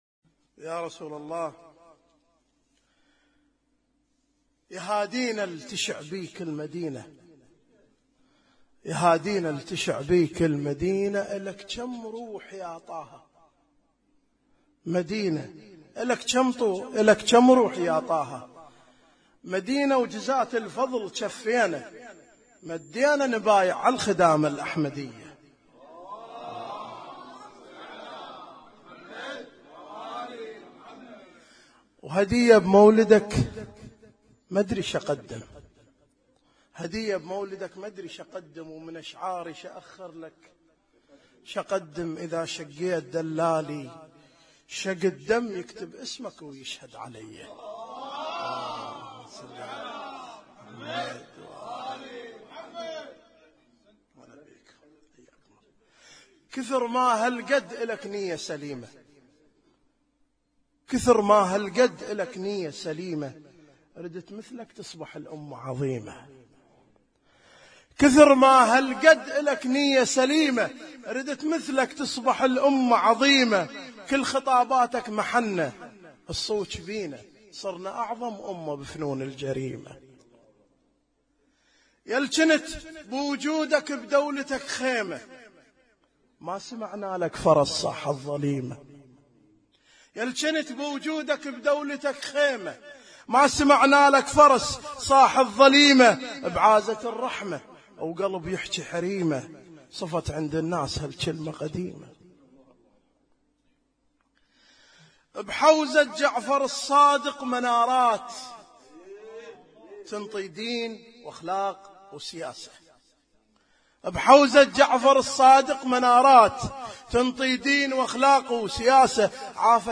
اسم النشيد:: قصيدة / حضرة الإسلام - ليلة 20 ربيع الأول 1437
اسم التصنيف: المـكتبة الصــوتيه >> المواليد >> المواليد 1437